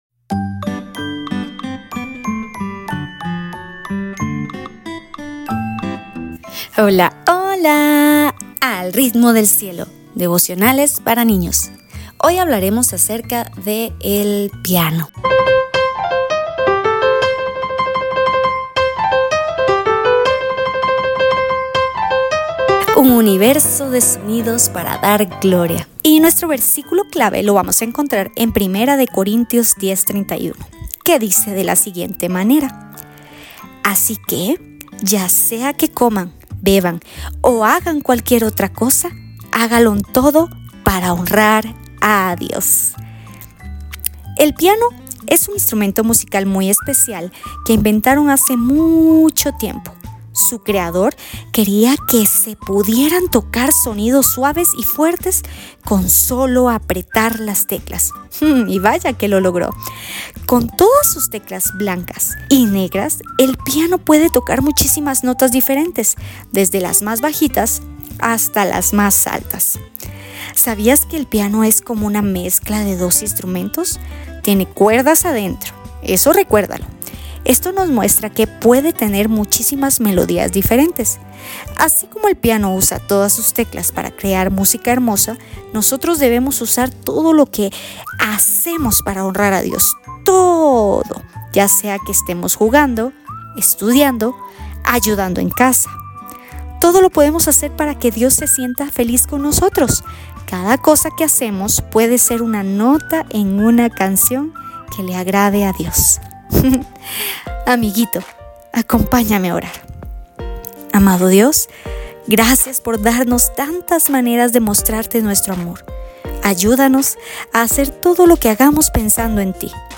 – Devocionales para Niños